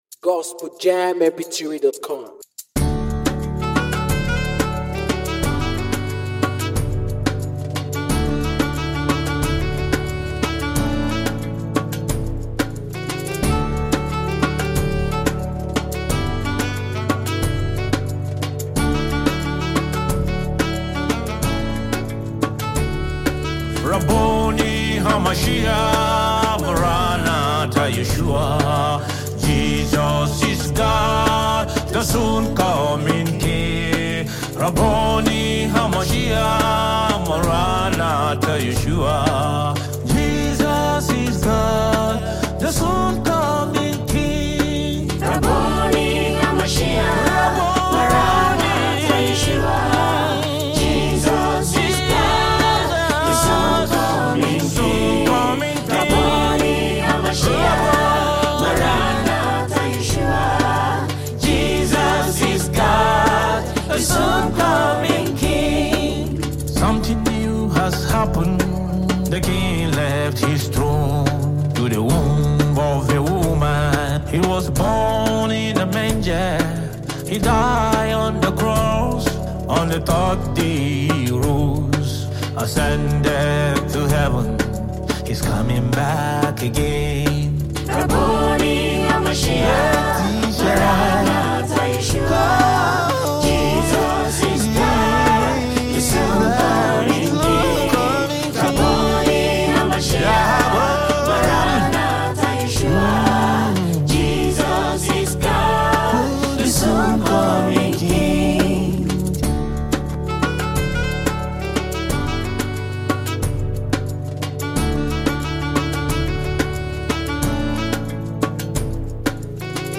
A Gospel Anthem of Hope and Devotion